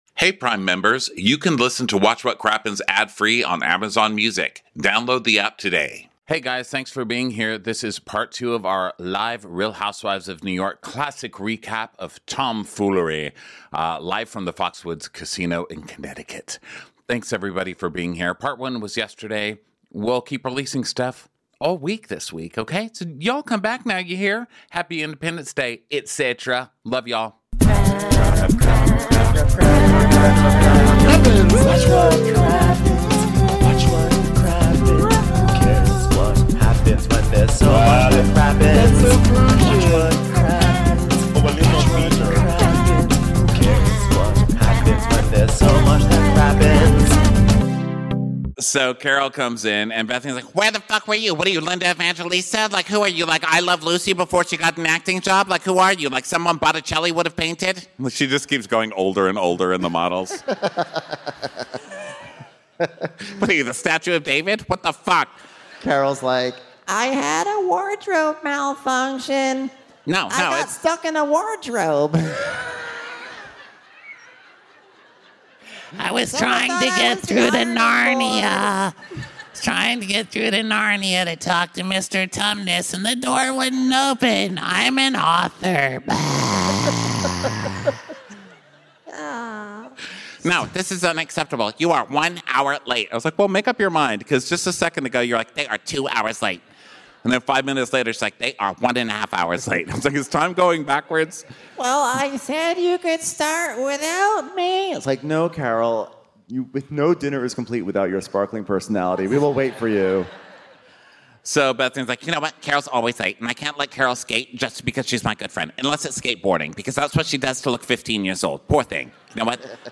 We're back live from the Foxwoods Casino to conclude our recap of Real Housewives of New York's "Tomfoolery". Luann got the yacht, but will she keep it?